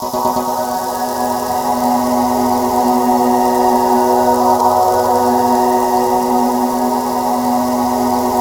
SANDSHOWER-L.wav